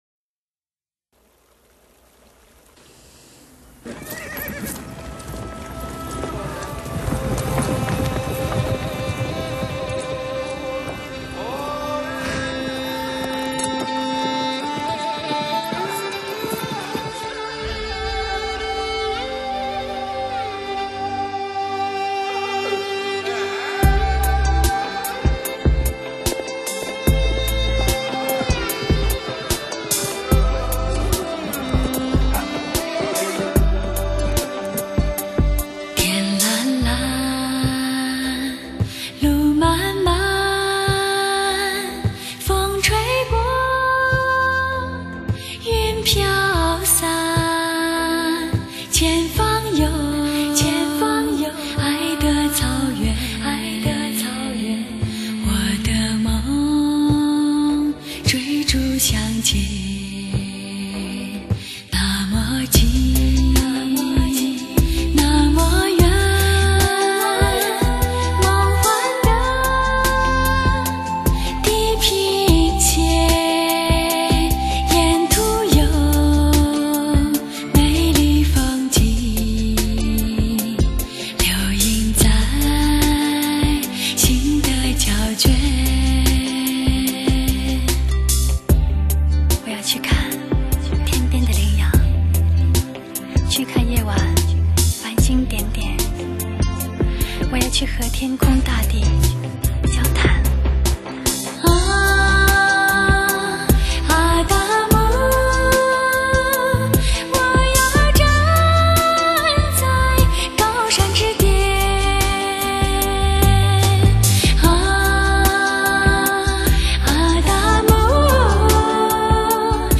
音质方面拥有无与伦比的通透，各类音色对比鲜明，
乐器演奏活灵活现，靓绝深情的旋律，音效极致HI-FI，